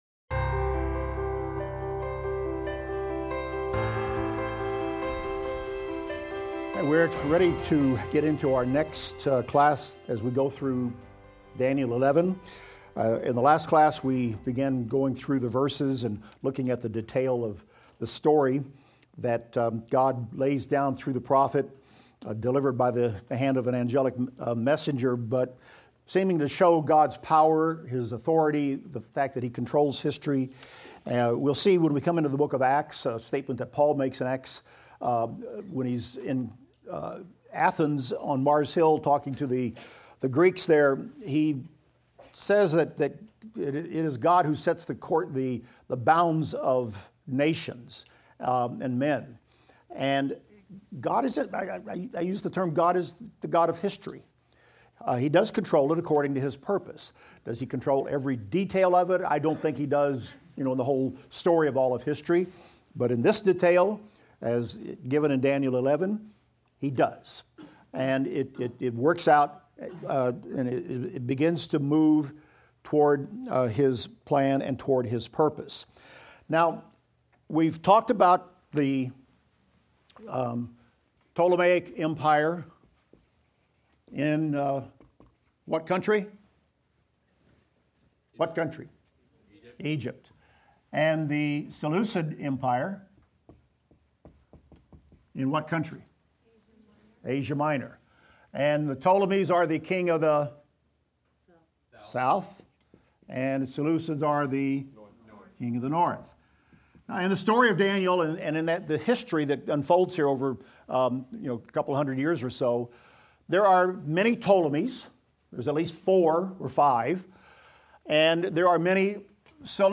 Daniel - Lecture 19 - audio.mp3